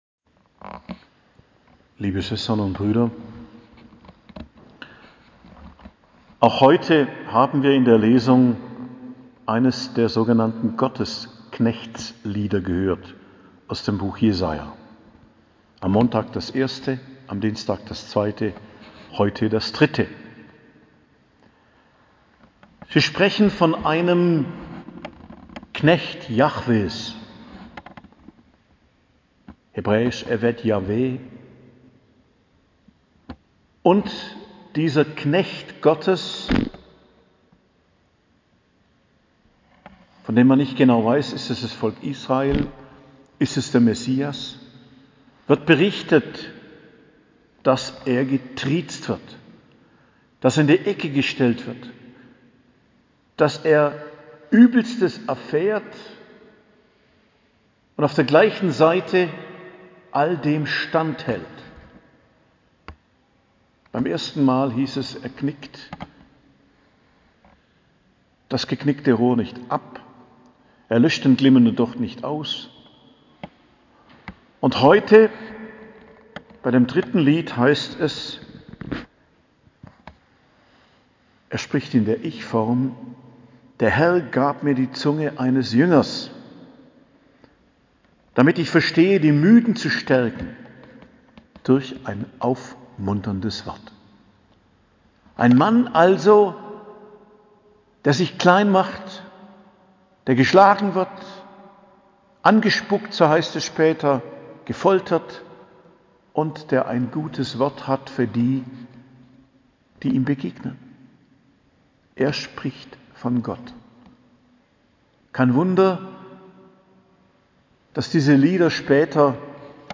Predigt am Mittwoch der Karwoche, 13.04.2022